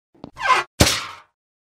Among Us (going in vent) sound effects free download
Among Us (going in vent) - Meme Sound Effect